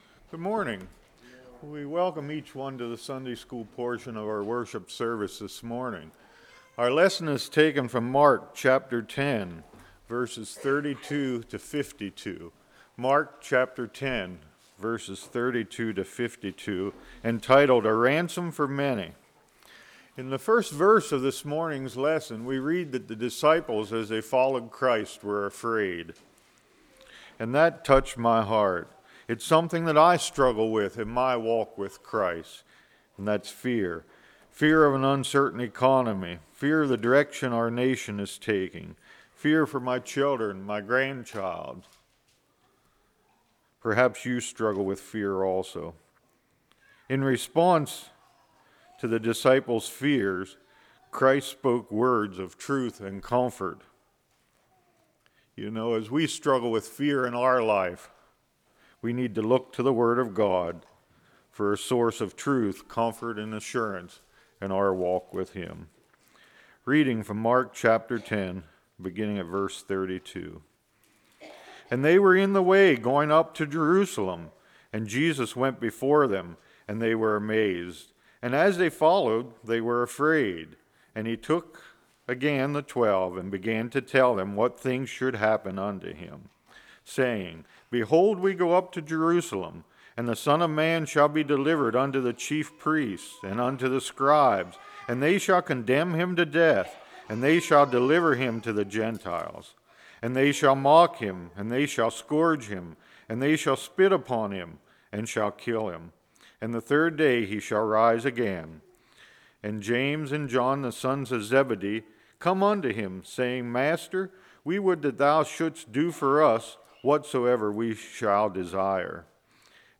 Series: Spring Revival 2015
Service Type: Sunday School